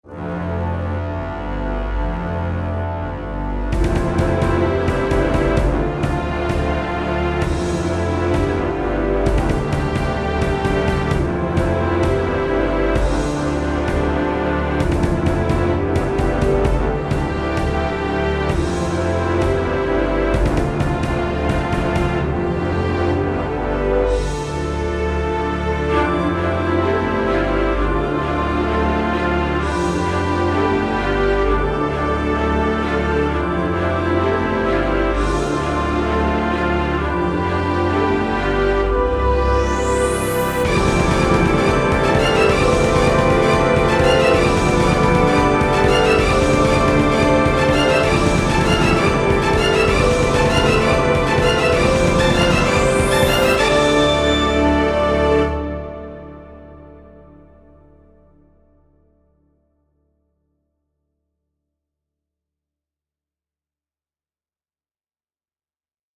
A short orchestral music I made for the OST of my game